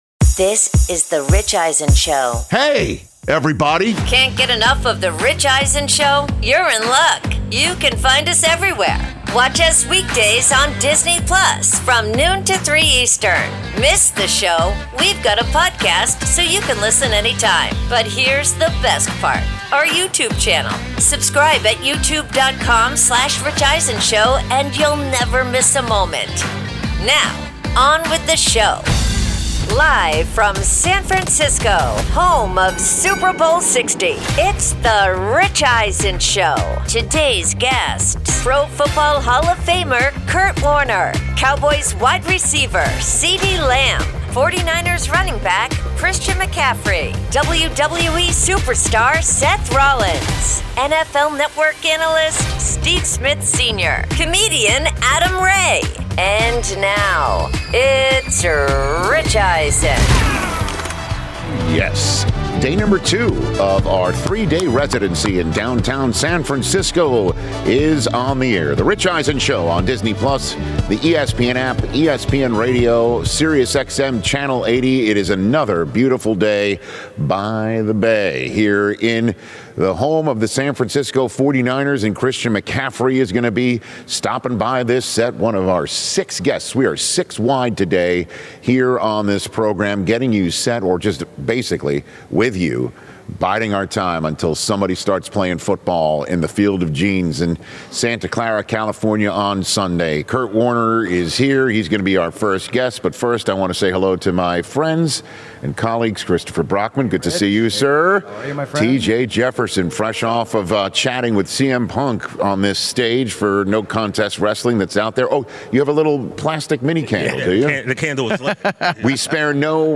Hour 1: Live from Super Bowl LX with Kurt Warner & WWE Superstar Seth Rollins